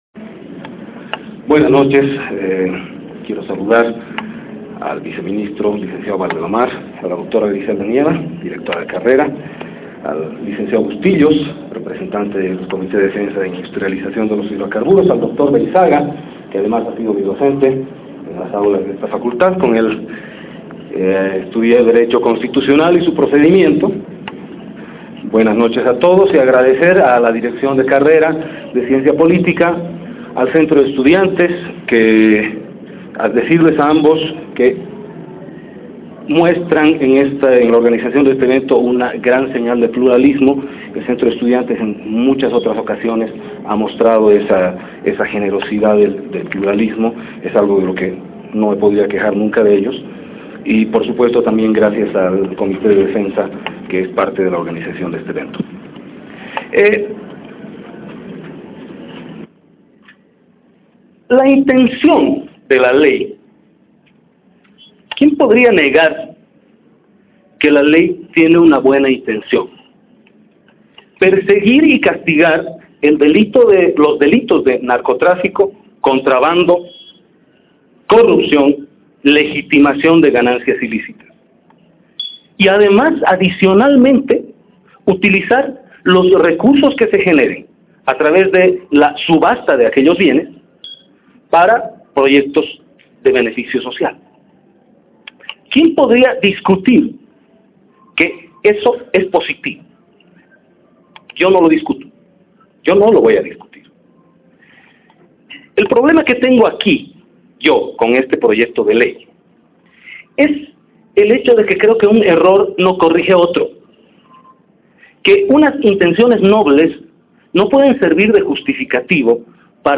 Breve ponencia realizada sobre el Proyecto de Ley de Extinción de Dominio de Bienes a Favor del Estado, pronunciada en el auditorio de la Facultad de Ciencias Jurídicas y Políticas de la Universidad Mayor de San Simón, en ocasión de la semana aniversario de la Carrera de Ciencia Política y por invitación de la Dirección y Centro de Estudiantes de dicha Carrera.